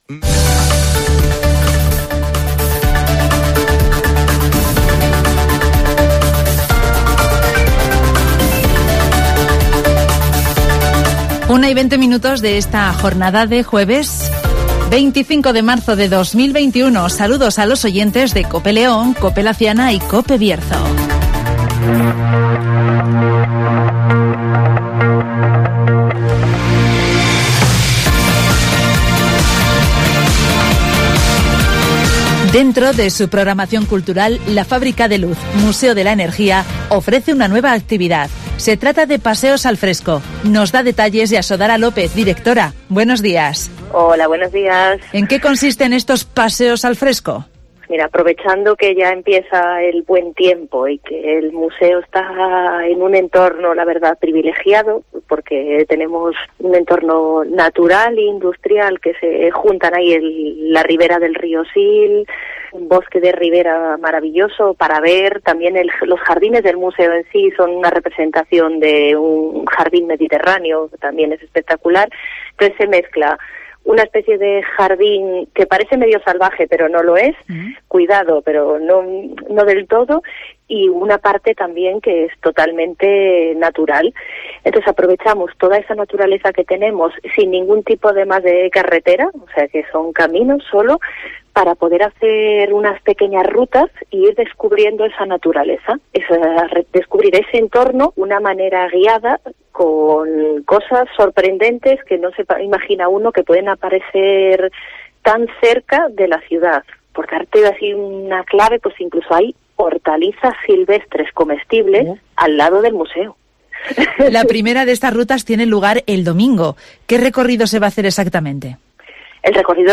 El Museo de la Energía de Ponferrada pone en marcha 'Paseos al fresco', rutas entorno a la antigua central (Entrevista